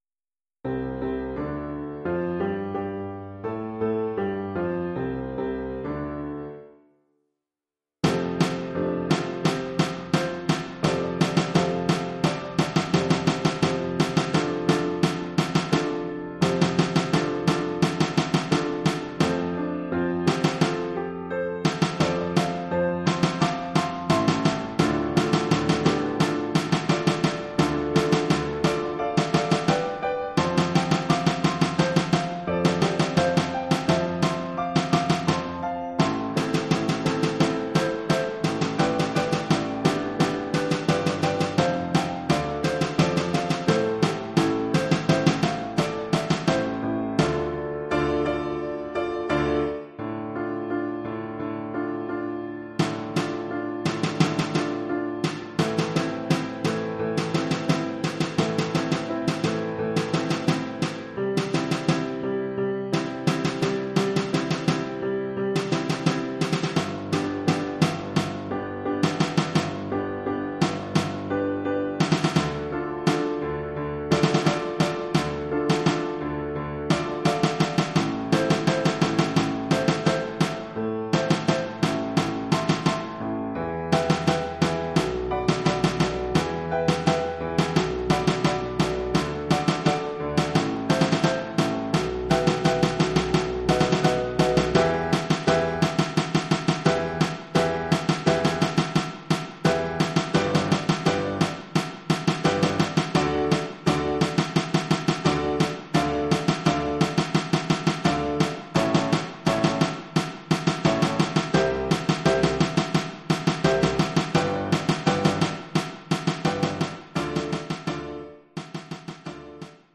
Oeuvre pour caisse claire et piano.